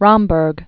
(rŏmbərg), Sigmund 1887-1951.